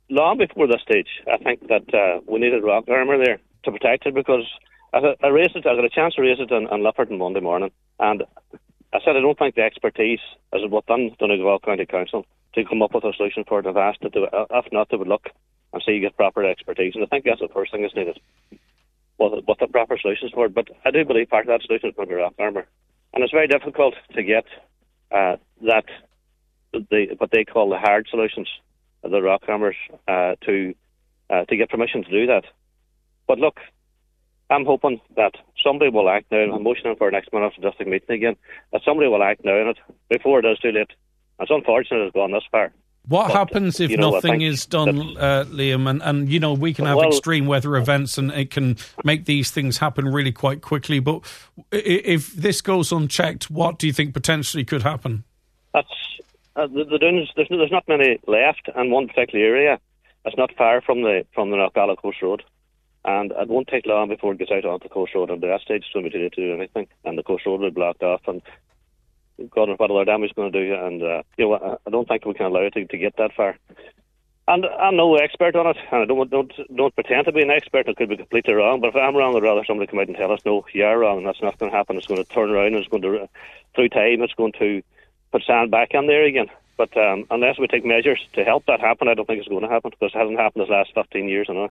Cllr Blaney warned that the erosion could eventually lead to a road obstruction: